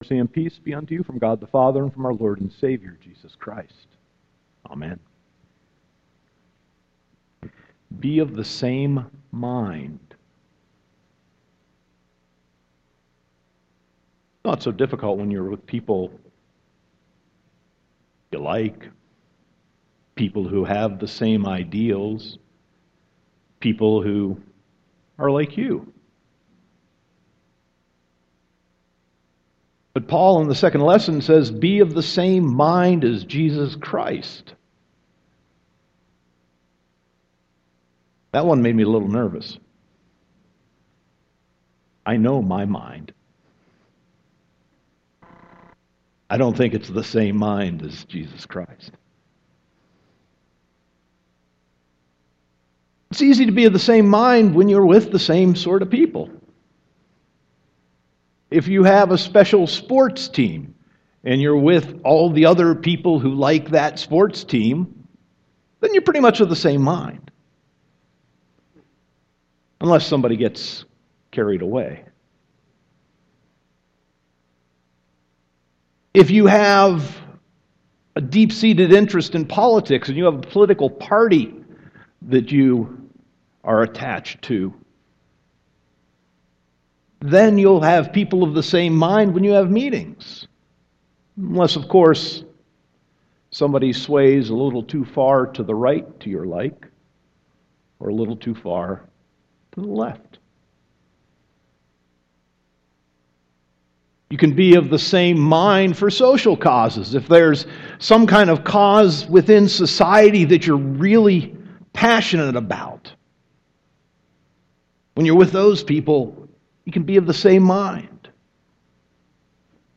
Sermon 10.1.2017